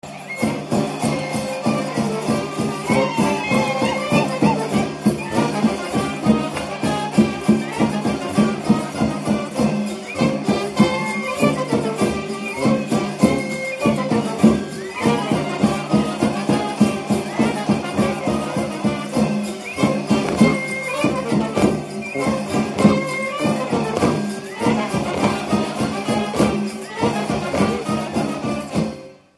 Dallampélda: Hangszeres felvétel
Műfaj: Friss csárdás Gyűjtő